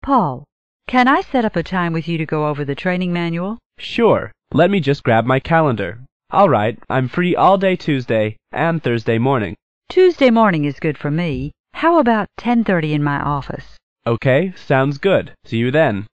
办公室英语会话第44期-Training appointment 约定培训时间